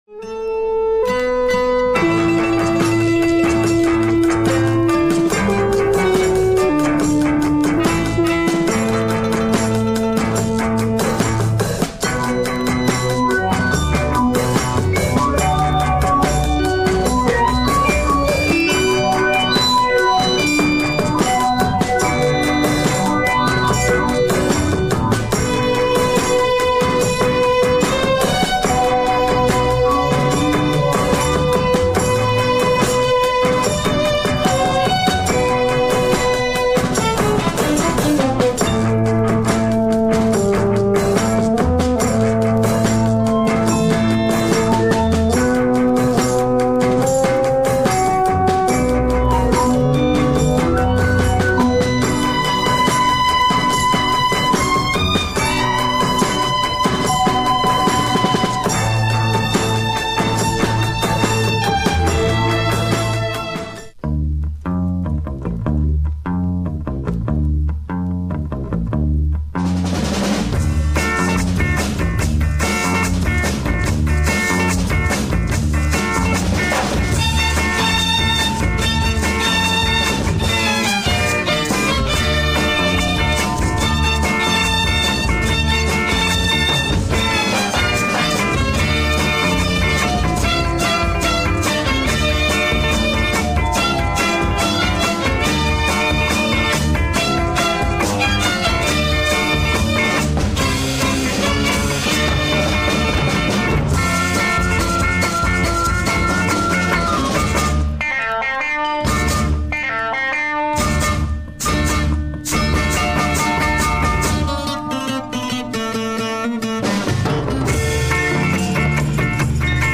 Fantastic groovy exotic psychedelia !